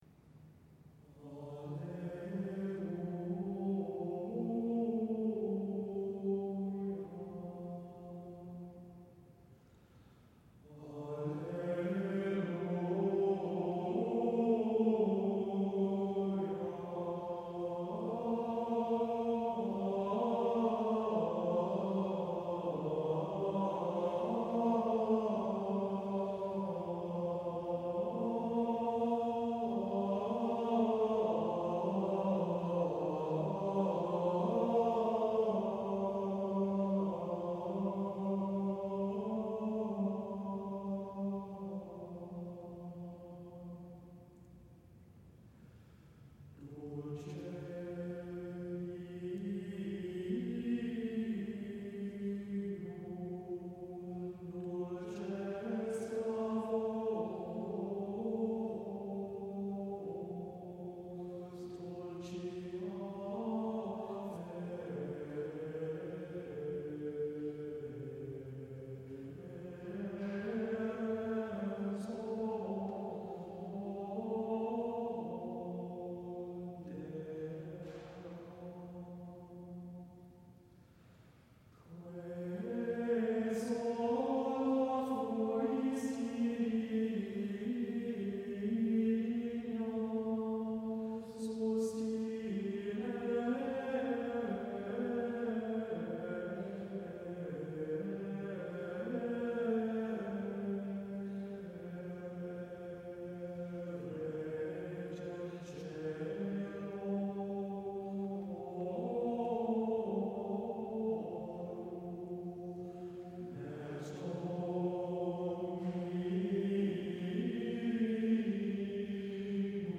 an adult mixed choir of 23 amateur singers
sublime polyphony